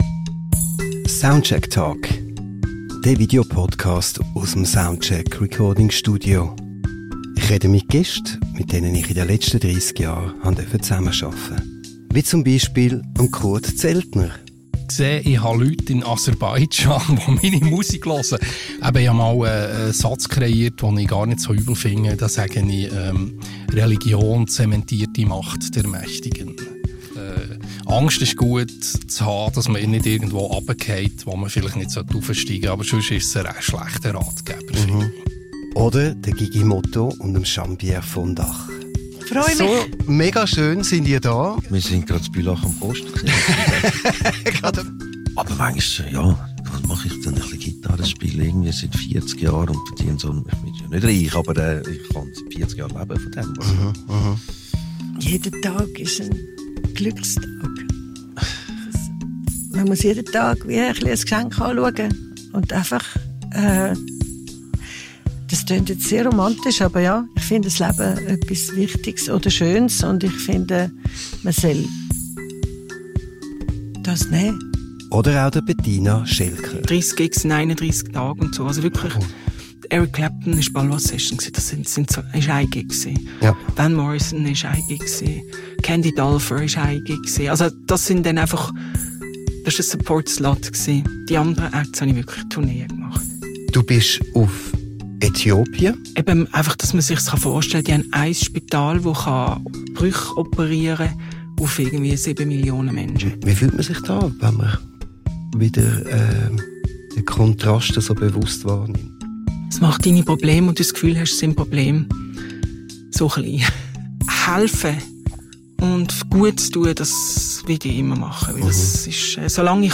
Piano für einen gemeinsamen Song – live im Studio.